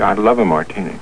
Sound Effects for Windows
martini.mp3